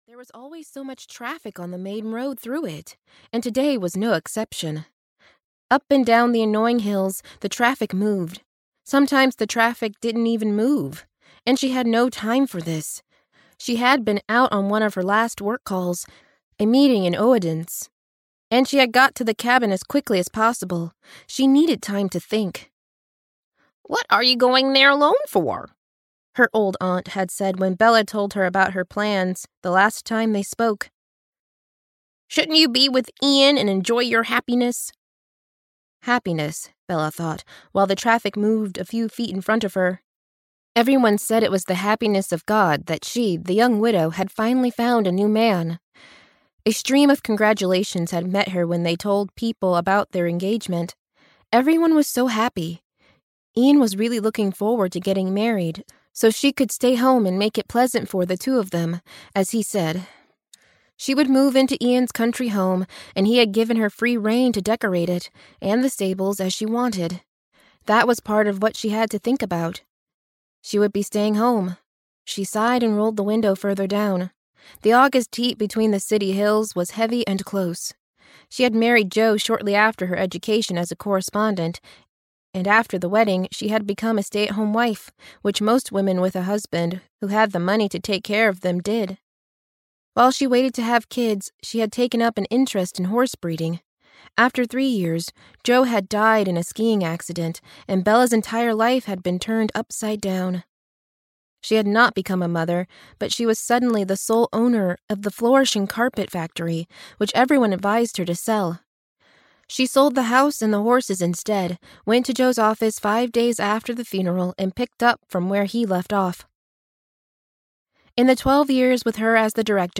Cabin Fever 6: Freyja's Lair (EN) audiokniha
Ukázka z knihy